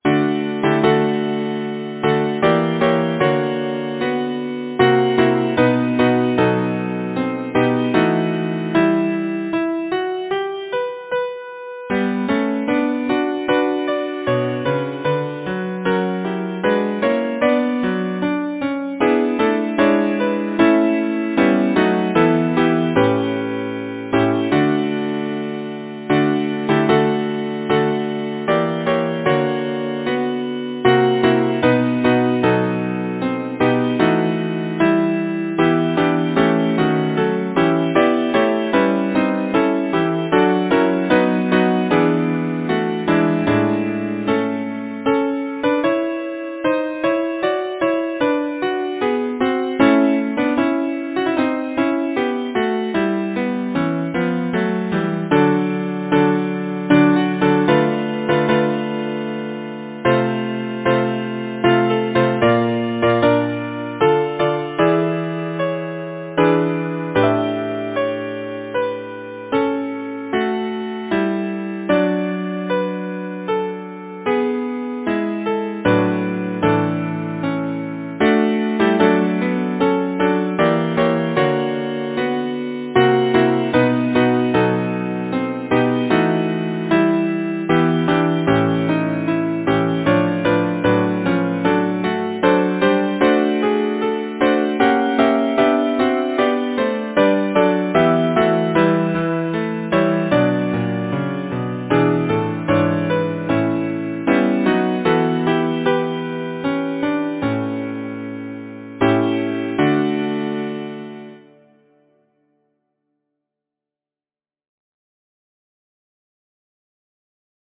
Title: The trysting tree Composer: George J. Bennett Lyricist: Number of voices: 4vv Voicing: SATB Genre: Secular, Partsong
Language: English Instruments: A cappella